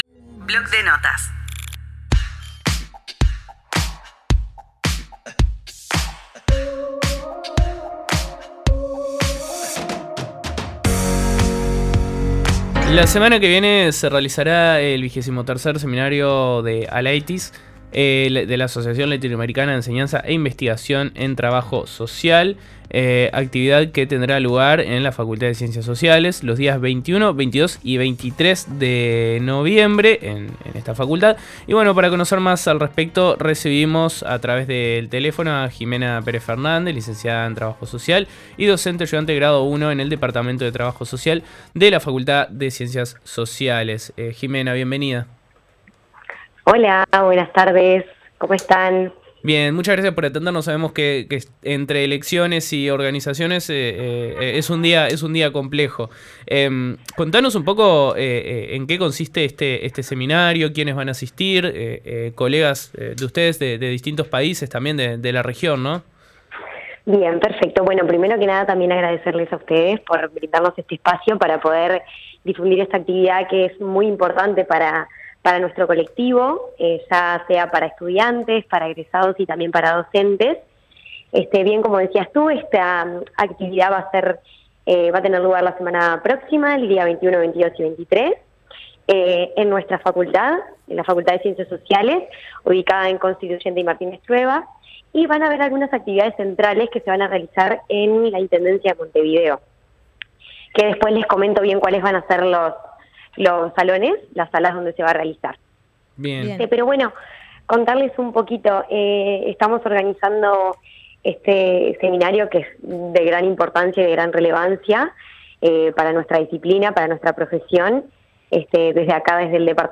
Los estudiantes practicantes de UNI Radio realizaron una cobertura especial de las Elecciones Universitarias.